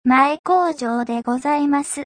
京町セイカ(きやうまちセイカボイスロイド)